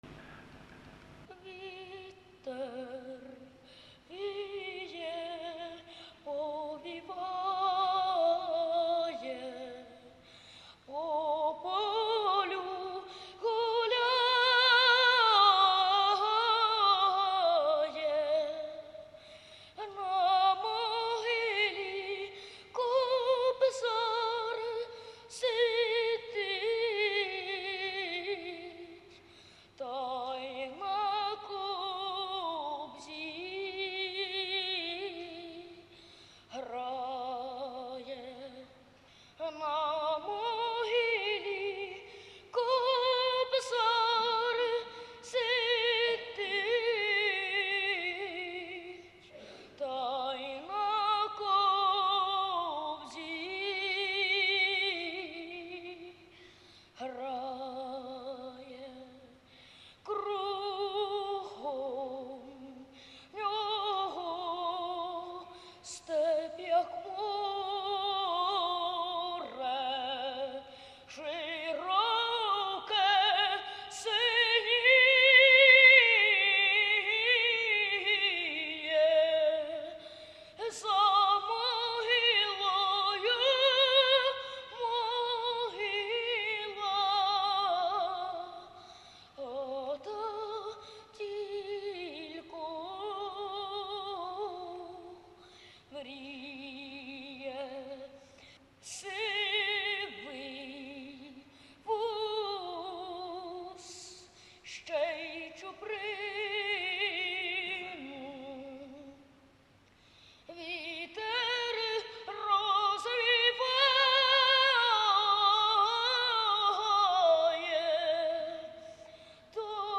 аудіоальбом з концертних записів